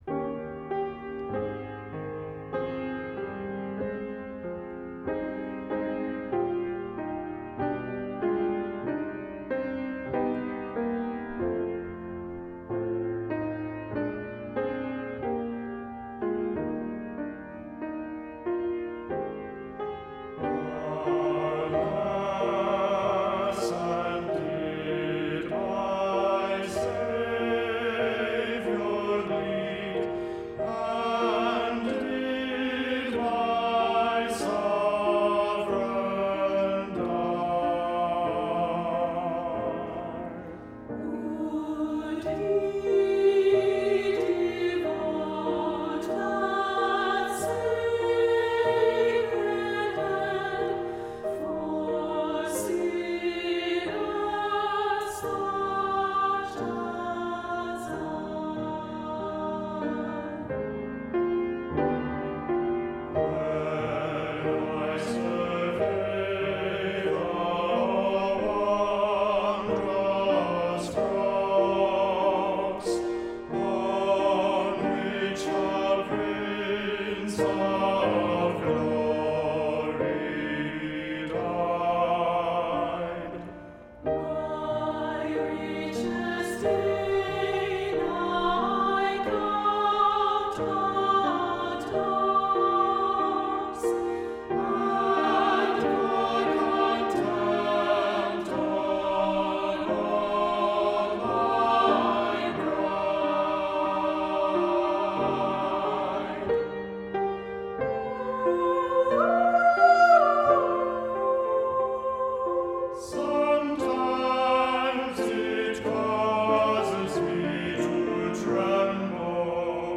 Voicing: "Two-part"